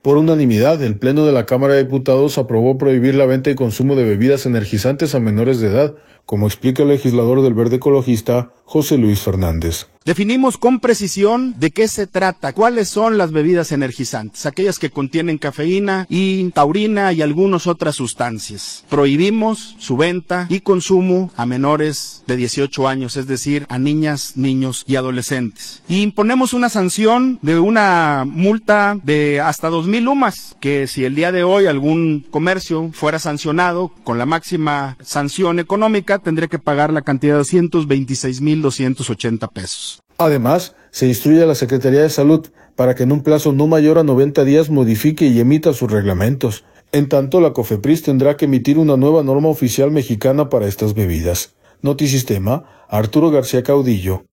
Por unanimidad, el Pleno de la Cámara de Diputados aprobó prohibir la venta y consumo de bebidas energizantes a menores de edad, como explica el legislador del Verde Ecologista, José Luis Fernández.